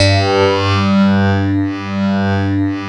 66 CLAV   -L.wav